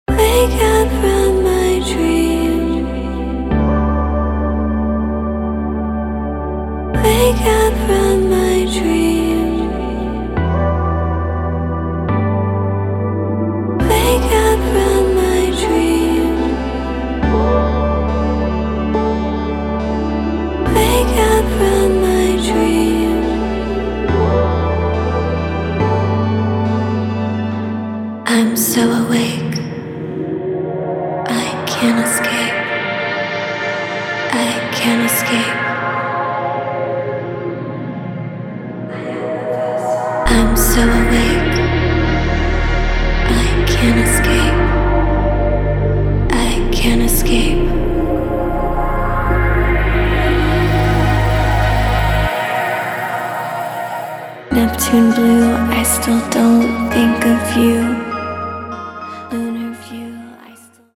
Hyperpop
dark spoken-word delivery